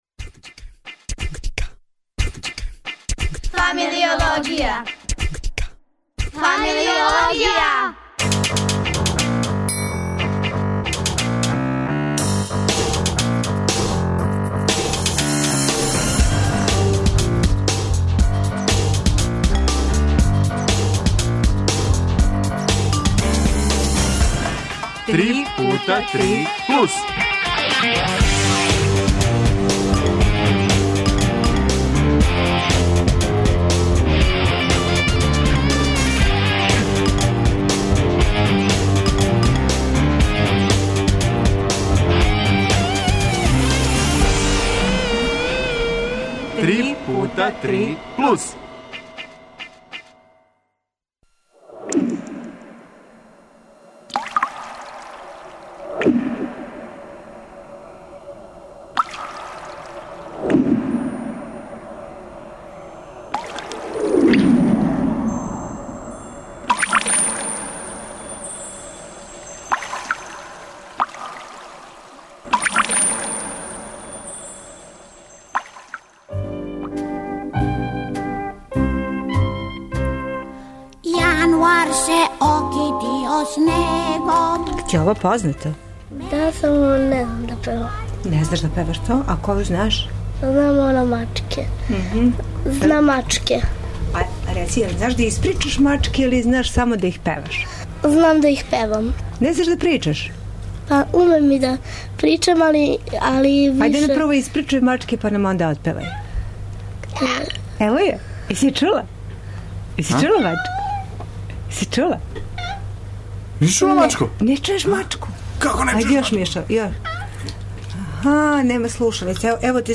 Плус су деца и одрасли из сликарске школе.